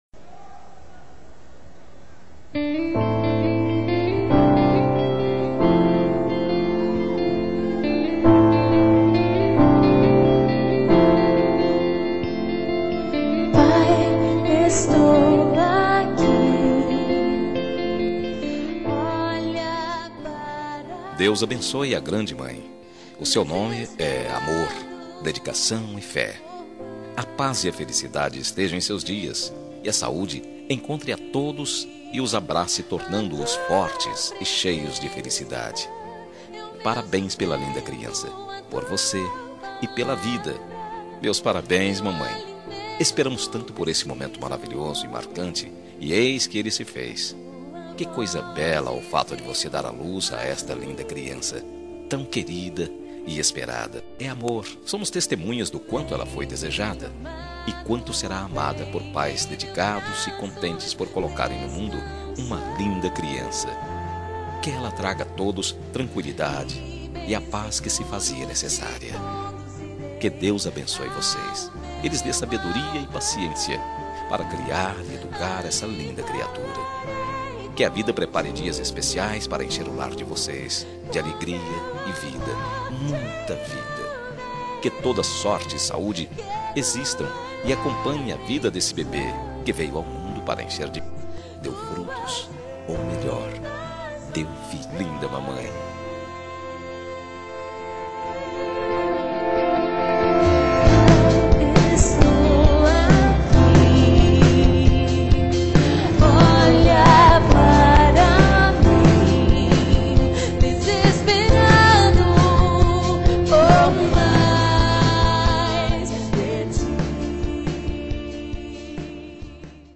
TELEMENSAGEM EVANGÉLICA MATERNIDADE
Voz Masculina